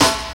23 SNARE 2.wav